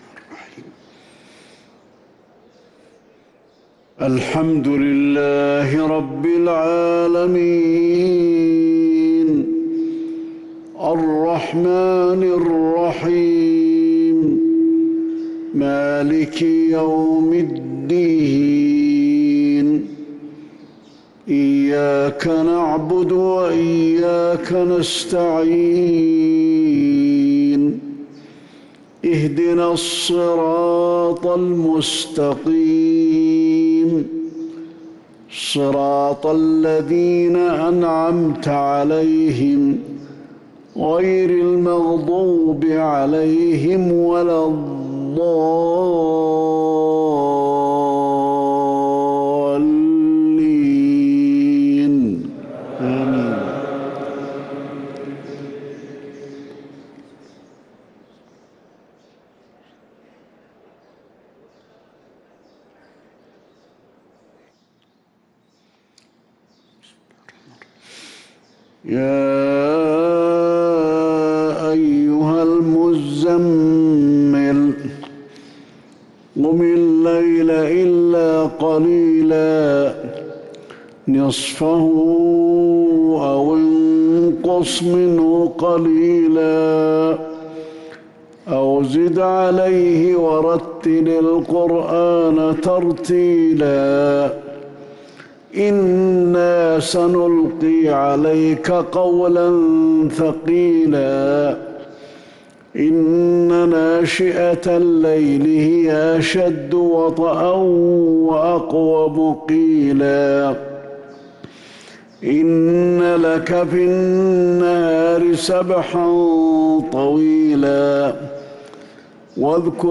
صلاة الفجر للقارئ علي الحذيفي 22 ربيع الآخر 1445 هـ
تِلَاوَات الْحَرَمَيْن .